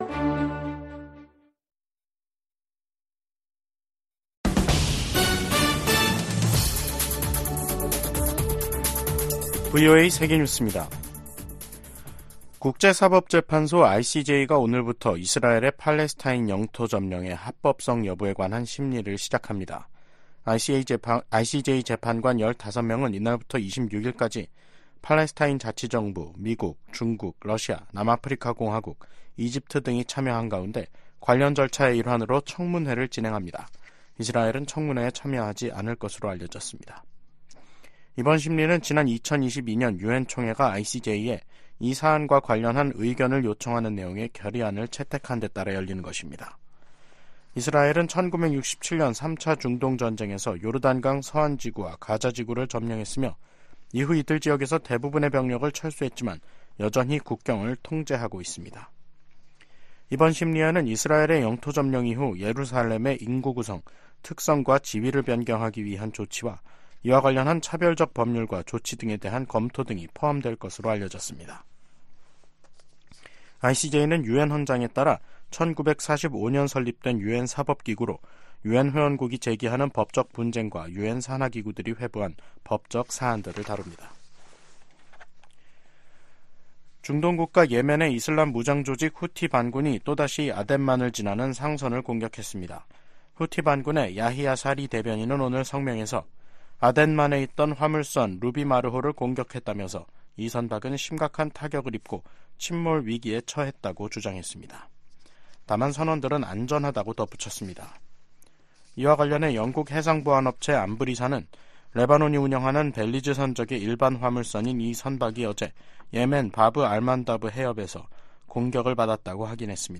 VOA 한국어 간판 뉴스 프로그램 '뉴스 투데이', 2024년 2월 19일 2부 방송입니다. 미 국무부는 러시아의 북한산 탄도미사일 사용 사실을 확인하고 모든 수단을 동원해 북-러 무기 거래를 막을 것이라고 강조했습니다. 백악관은 북한-일본 정상회담 추진 가능성에 대해 지지 입장을 밝히고 미한일 협력에 균열 우려는 없다고 밝혔습니다. 북한이 핵·미사일 역량을 키우면서 미국·동맹에 위협 수준이 점증하고 있다고 미 전략사령관이 지적했습니다.